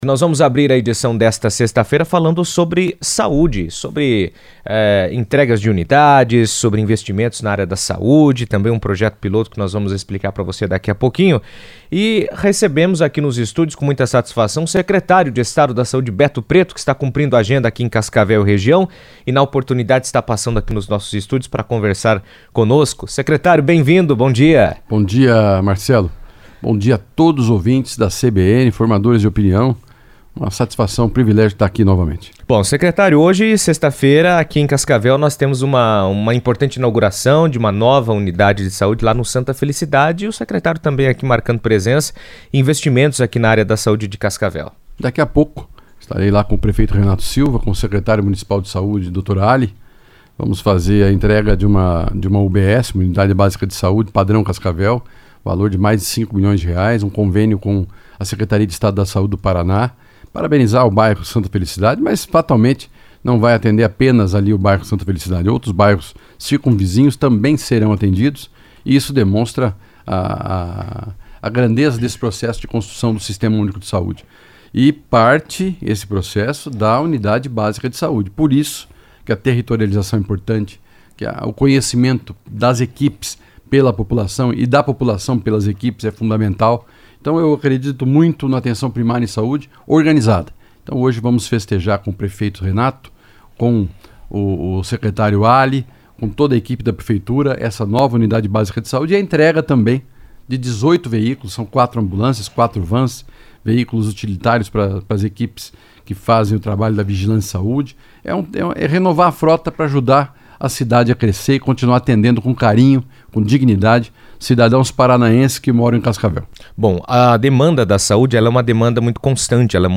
O Paraná foi selecionado pelo Ministério da Saúde para integrar um projeto-piloto do Sistema Único de Saúde (SUS) que prevê a migração no modelo de oferta de insulina, com foco em ampliar o acesso, qualificar o acompanhamento dos pacientes e modernizar a logística de distribuição do medicamento. O tema foi abordado pelo secretário de Estado da Saúde, Beto Preto, em entrevista à CBN, durante passagem por Cascavel, onde ele também cumpriu agenda oficial para a inauguração da Unidade de Saúde da Família (USF) Santa Felicidade, reforçando os investimentos do Estado na atenção básica.